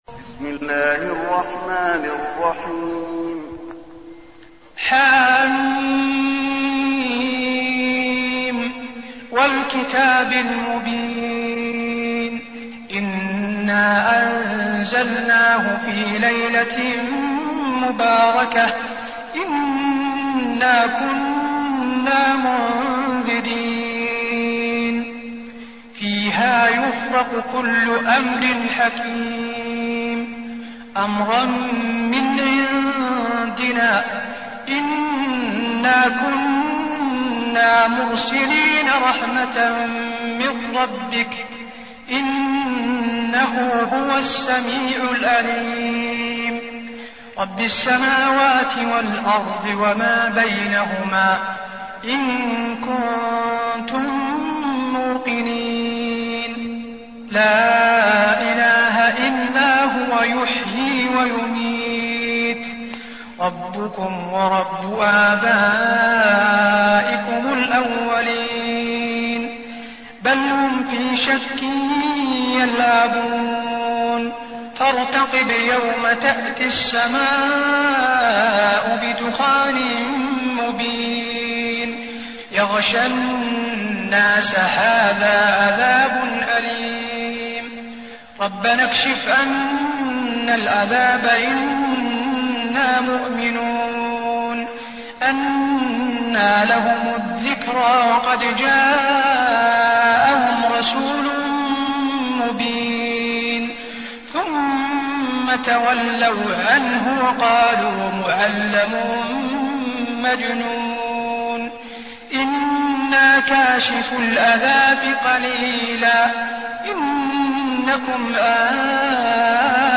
المكان: المسجد النبوي الدخان